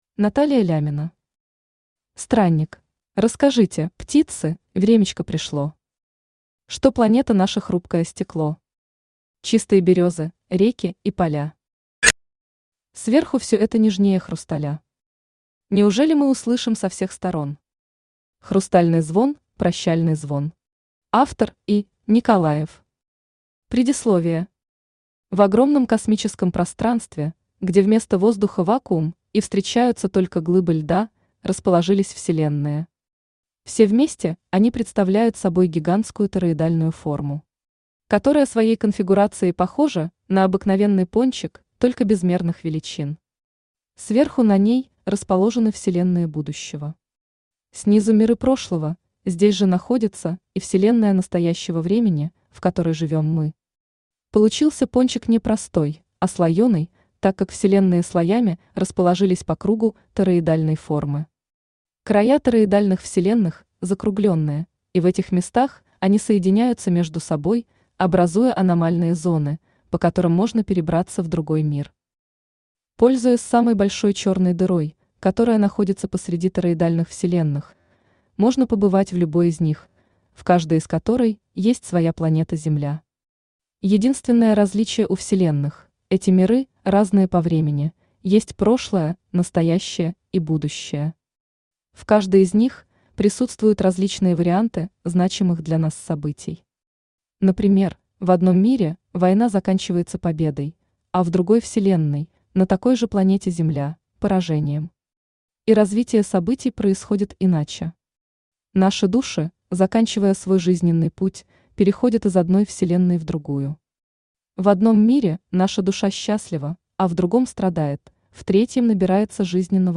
Аудиокнига Странник | Библиотека аудиокниг
Aудиокнига Странник Автор Наталия Леонидовна Лямина Читает аудиокнигу Авточтец ЛитРес.